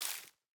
Minecraft Version Minecraft Version snapshot Latest Release | Latest Snapshot snapshot / assets / minecraft / sounds / block / leaf_litter / place5.ogg Compare With Compare With Latest Release | Latest Snapshot